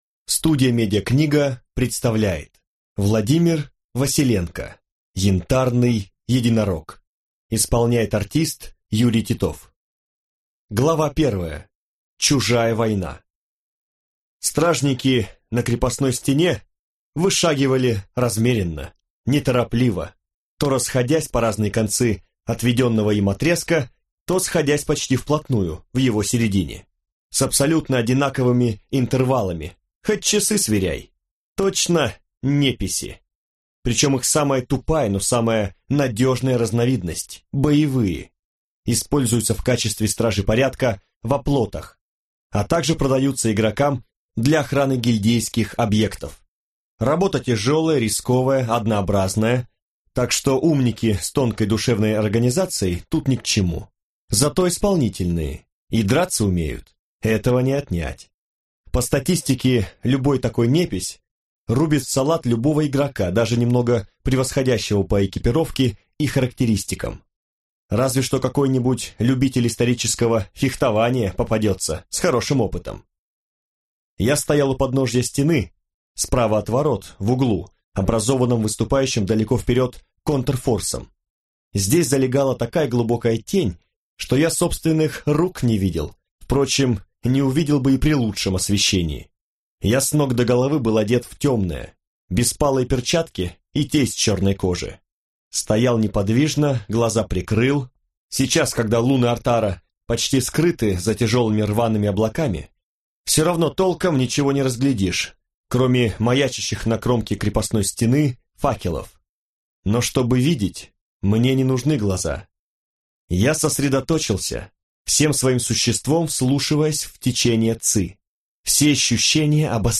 Аудиокнига Янтарный единорог | Библиотека аудиокниг